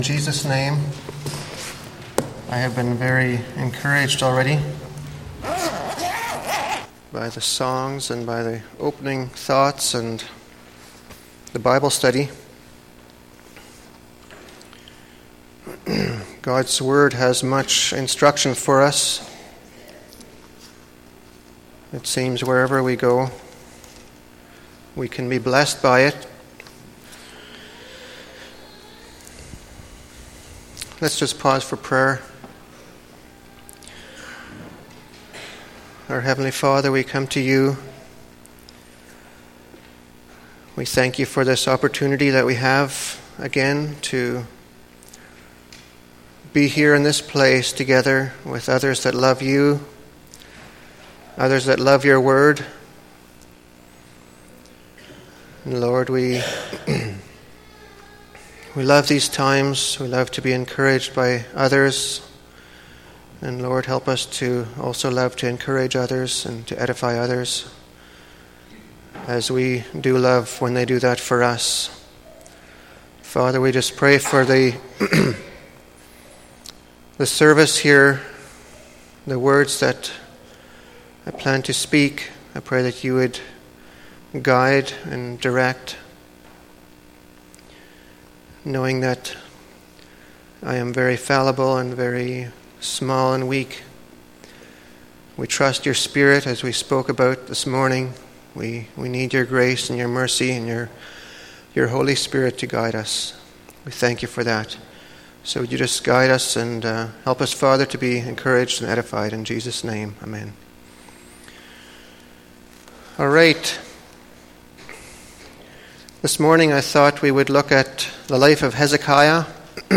Sermon
0009 Sermon.mp3